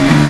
rr3-assets/files/.depot/audio/sfx/gearshifts/f1/renault_downshift_2.wav
renault_downshift_2.wav